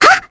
Mirianne-Vox_Attack3_kr.wav